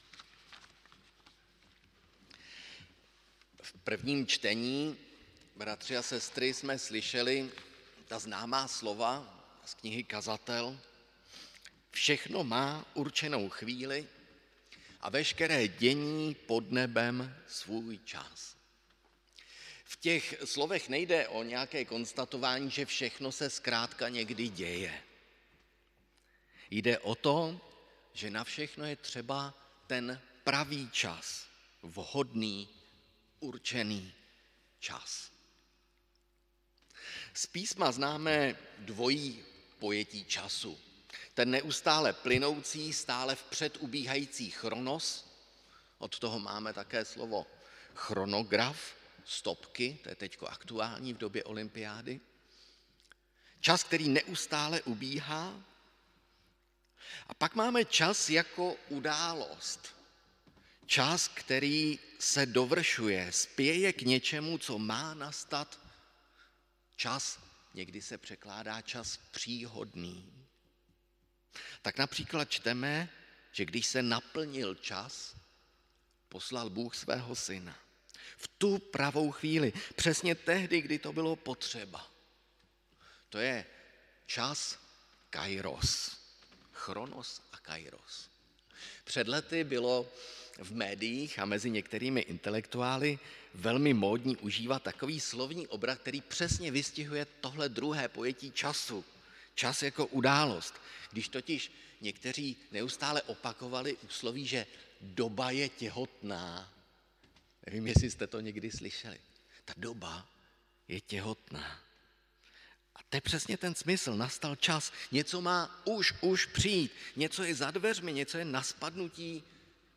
Bohoslužby 8. 8. 2021 • Farní sbor ČCE Plzeň - západní sbor